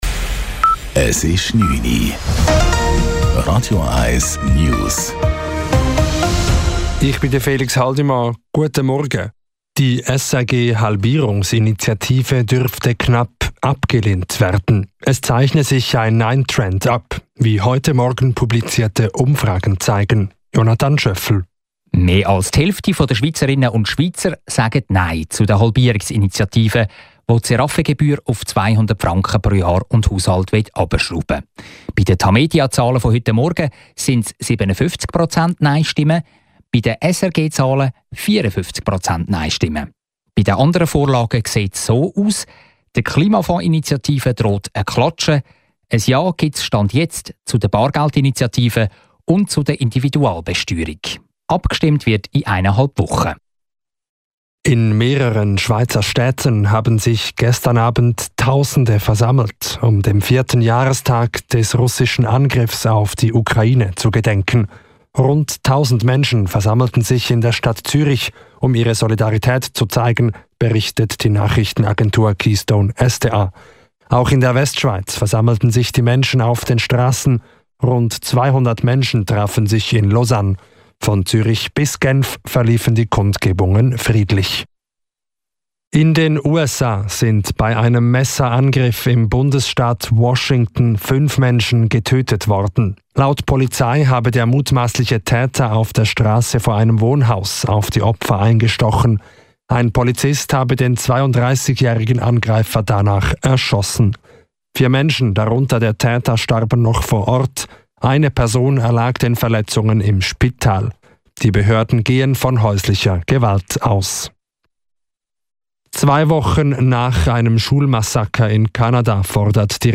Radio 1 News vom 25.02.2026 09:00
Nachrichten & Politik